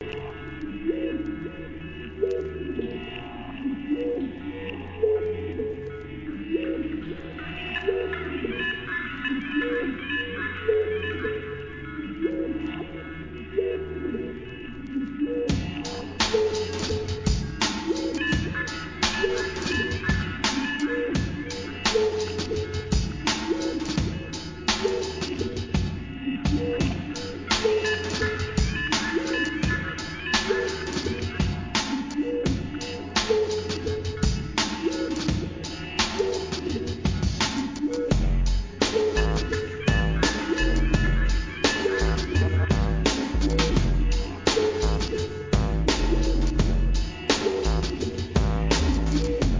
アンビエント、ブレイクビーツ!!